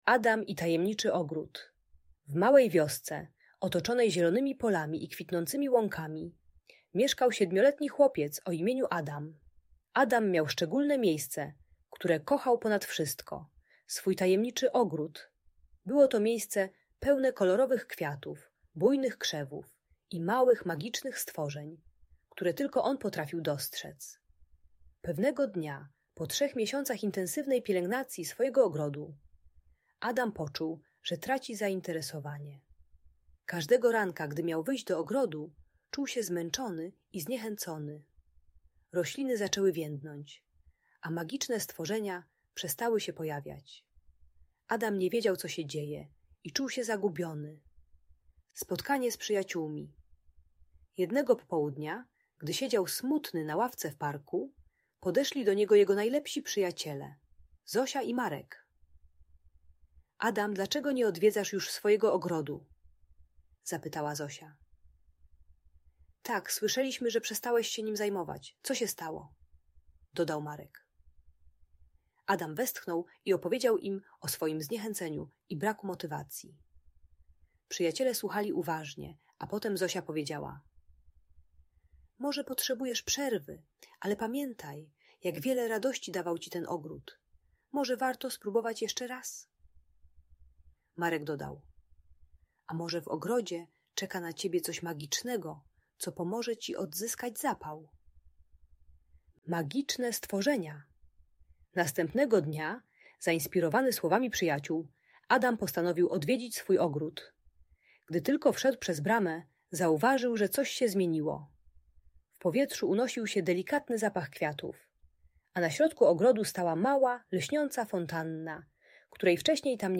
Historia uczy, jak radzić sobie ze zniechęceniem i wypaleniem, pokazując technikę małych kroków i szukania wsparcia u przyjaciół. Audiobajka o wytrwałości i pokonywaniu trudności.